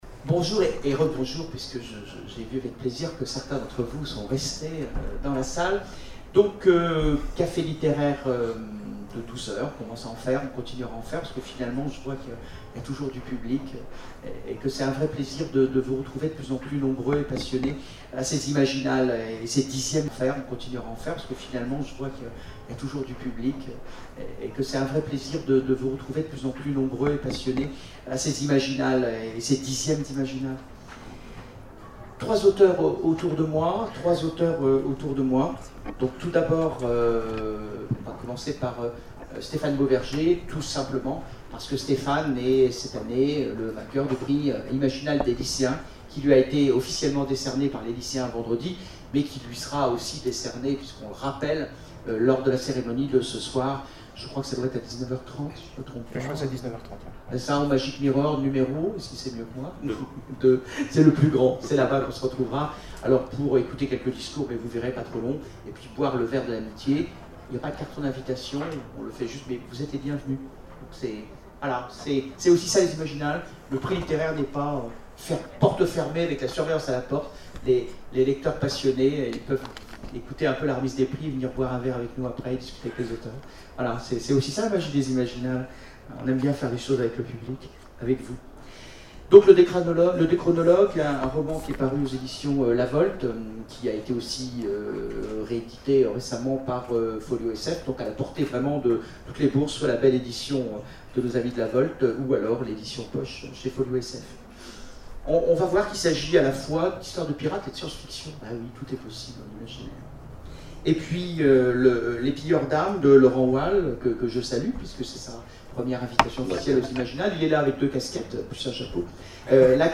Imaginales 2012 : Conférence Pirates en folie...